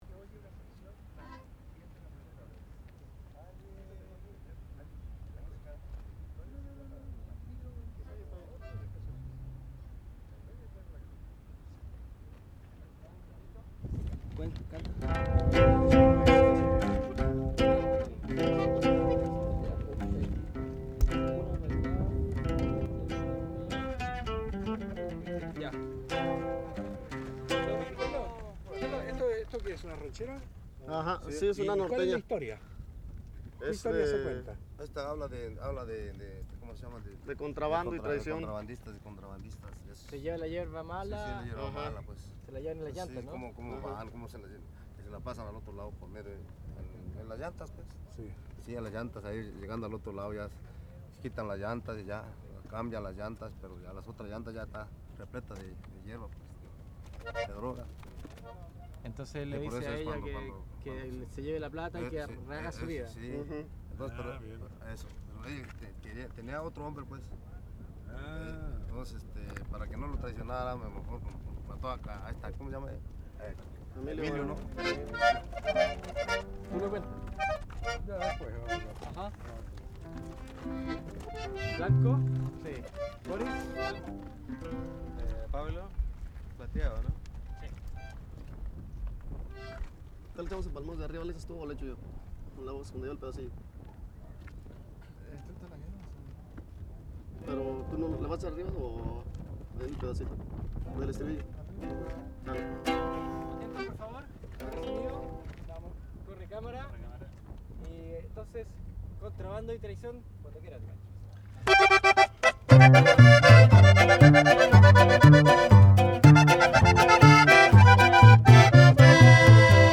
que estaban paseando cerca de la playa
corrido